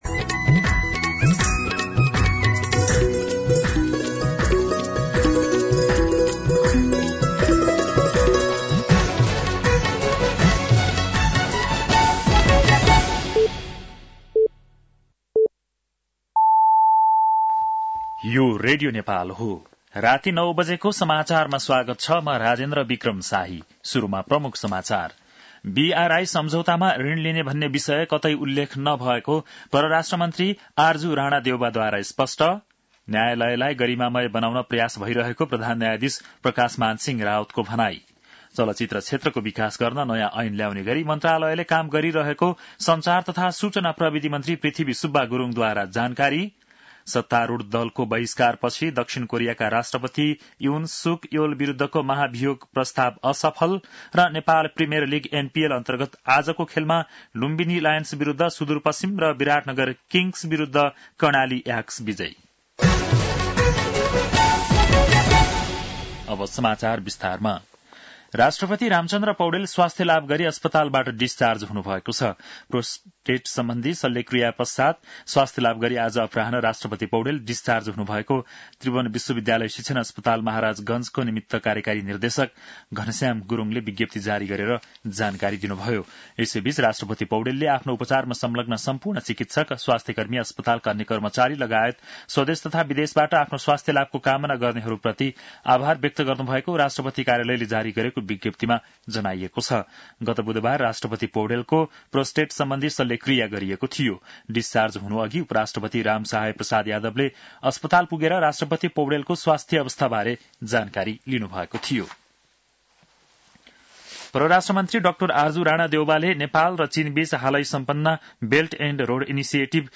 बेलुकी ९ बजेको नेपाली समाचार : २३ मंसिर , २०८१
9-PM-Nepali-News-8-22.mp3